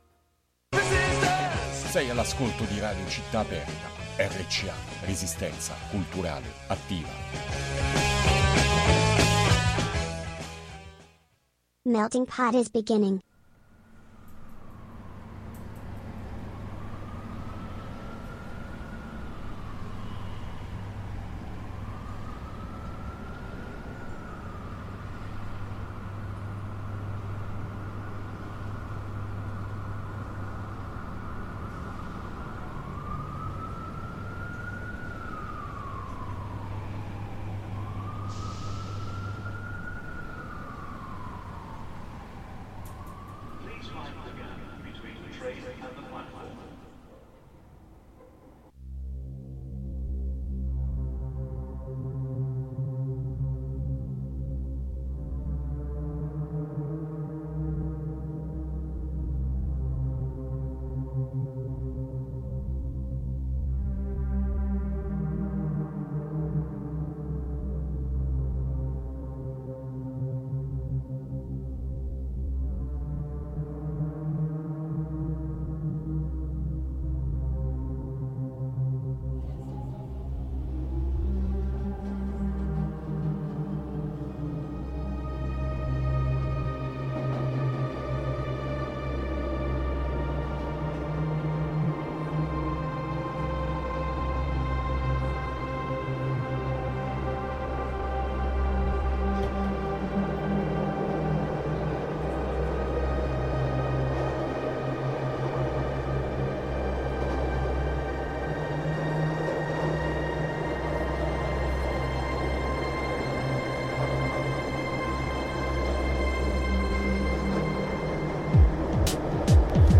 MUSICA E NOTIZIE A MELTING POT | Radio Città Aperta